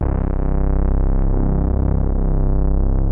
Moog Bass 2.wav